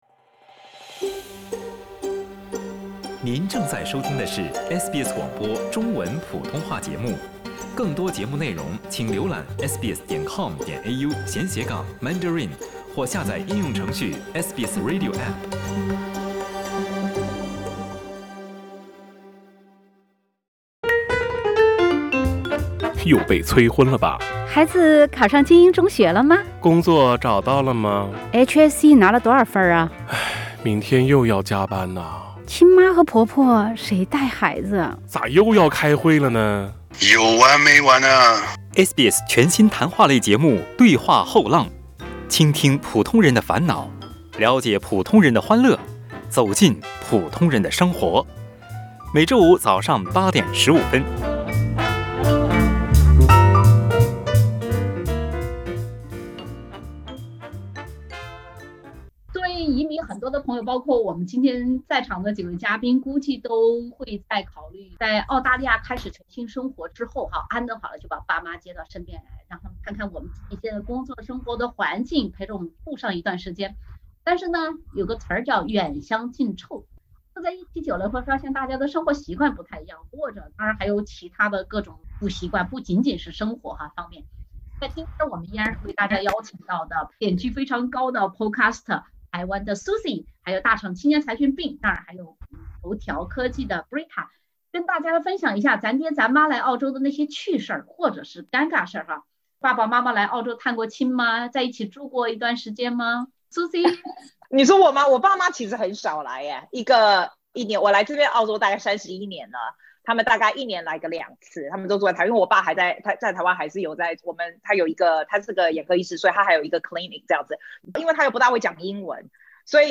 SBS全新谈话类节目《对话后浪》，倾听普通人的烦恼，了解普通人的欢乐，走进普通人的生活。